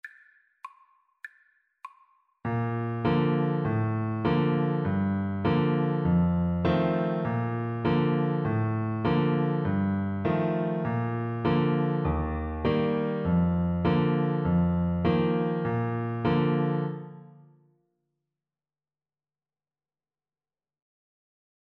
2/4 (View more 2/4 Music)
Playfully =c.100